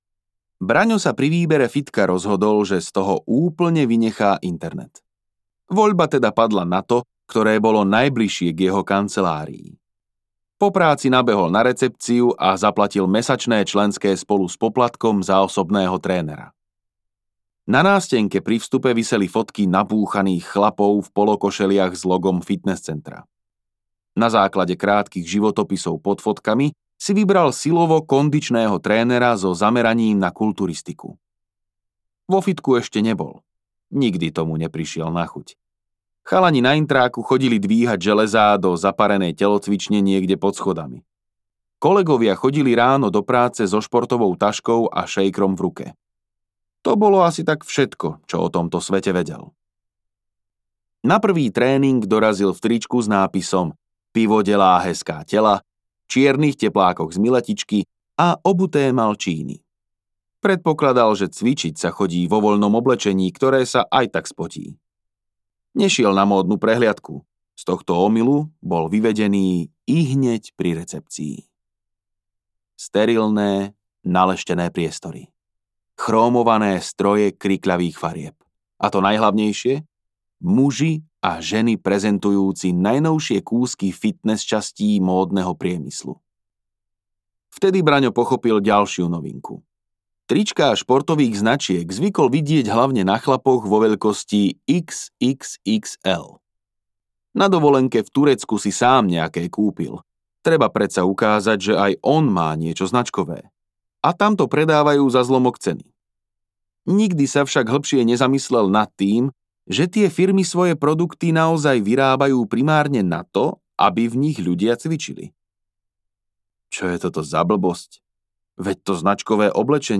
Hybaj! audiokniha
Ukázka z knihy